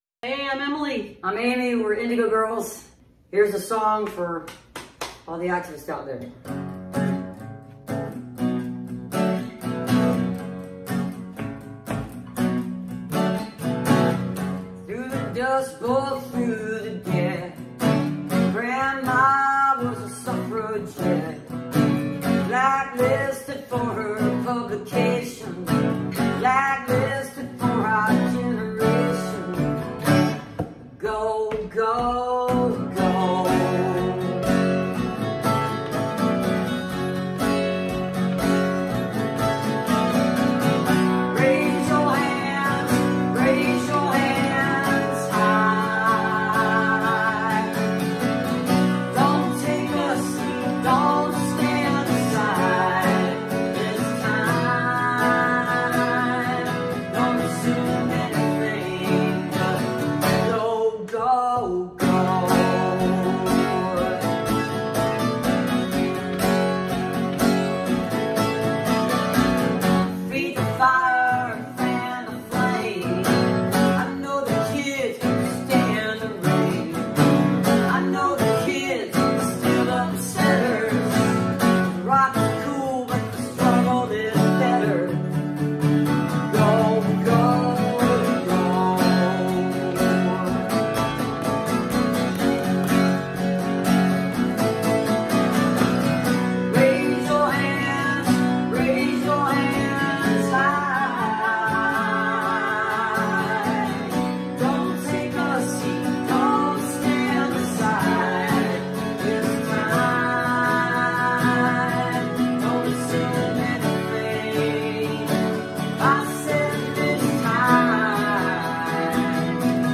(captured from youtube)